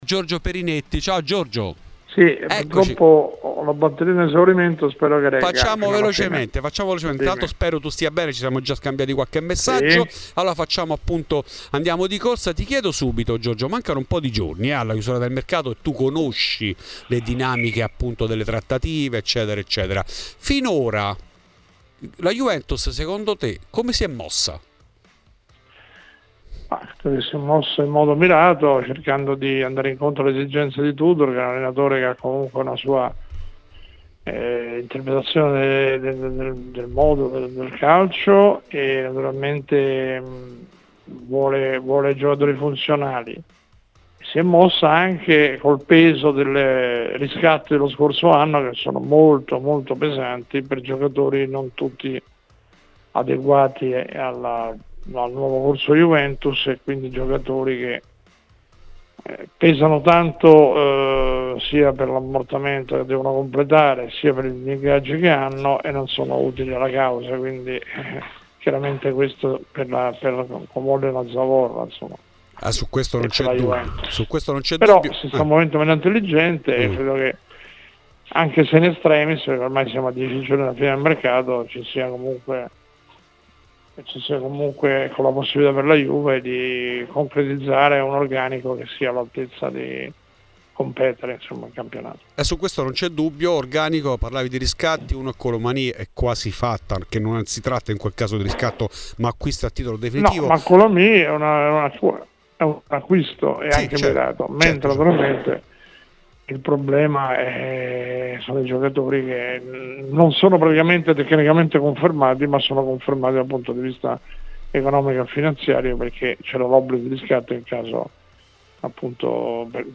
Ascolta il podcast per l'intervento integrale